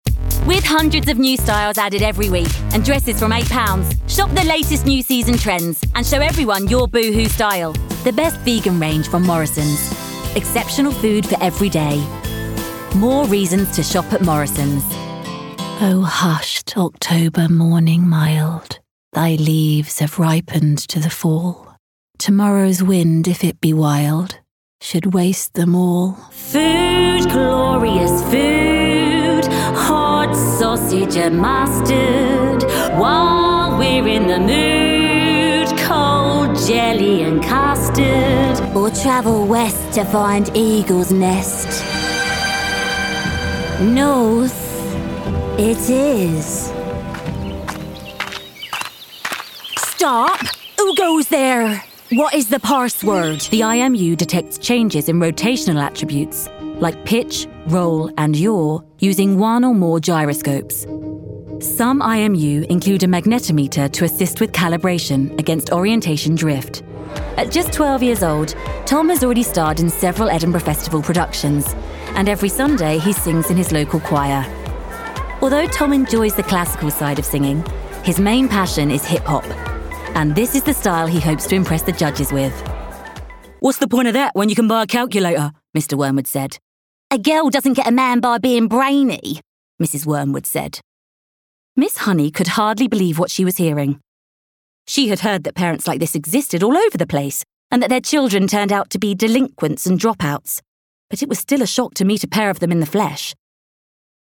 American, Bristol, Cockney, East End, Eastender, Essex, Estuary, London, Northern, RP ('Received Pronunciation'), Yorkshire
Fun Cool Friendly Relatable Commercial Versatile